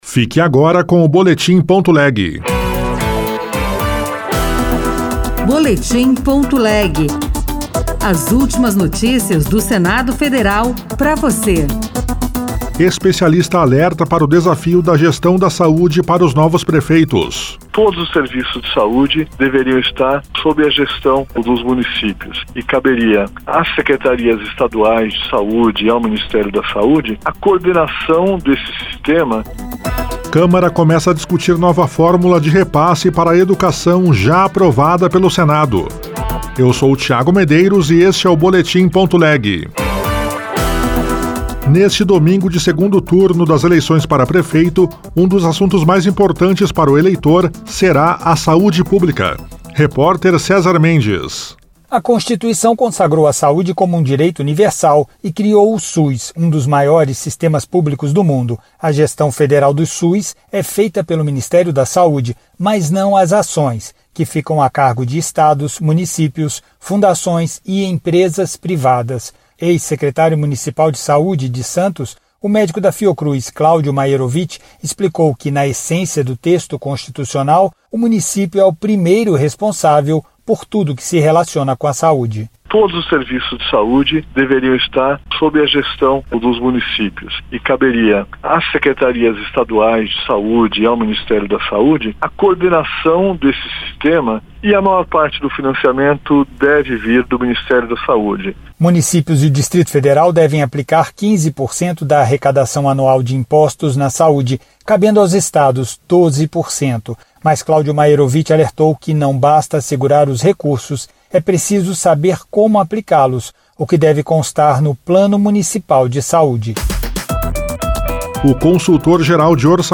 Boletim.leg - Edição das 14h — Rádio Senado